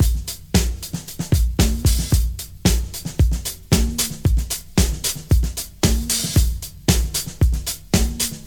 113 Bpm 80s Drum Loop A# Key.wav
Free drum loop - kick tuned to the A# note. Loudest frequency: 2385Hz